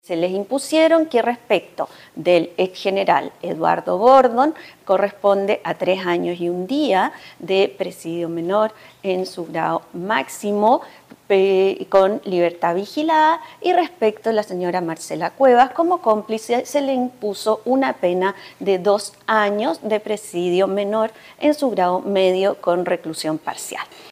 En ese marco, la mañana de este viernes en la misma sala penal de Santiago, se dio lectura a su sentencia, la que podrá cumplir en libertad.
En un fallo unánime, la jueza Patricia Brundl informó la sentencia al exgeneral Gordon de 3 años y un día, pena que podrá cumplir con una libertad condicional intensiva, es decir, en libertad.